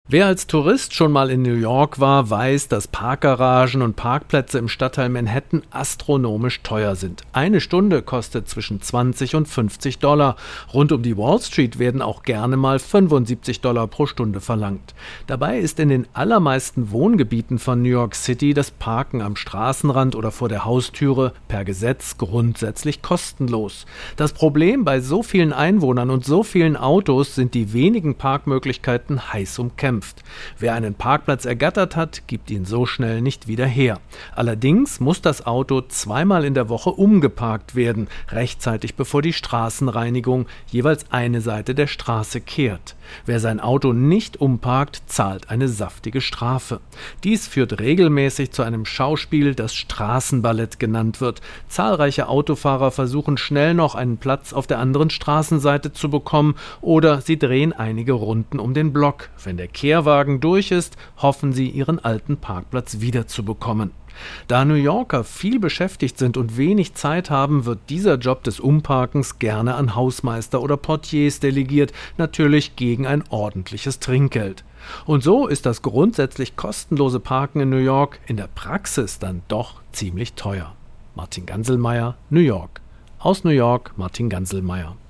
Korrespondent